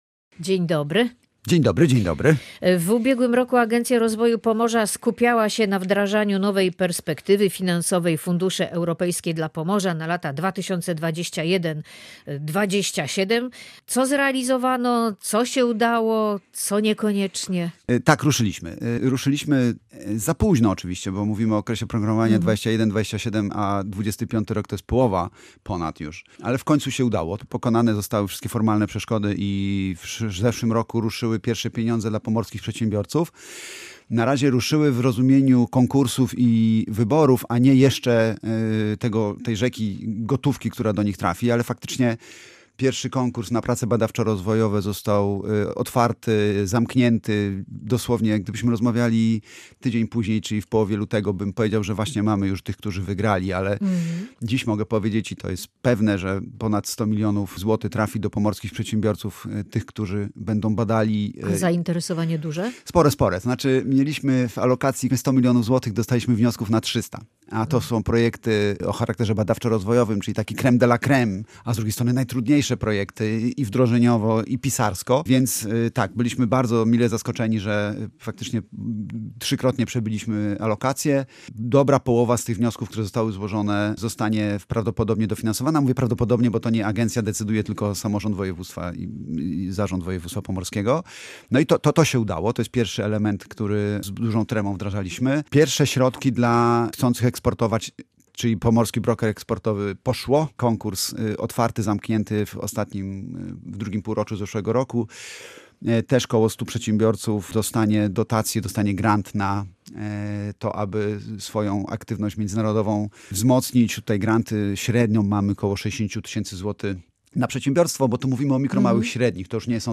O tym w rozmowie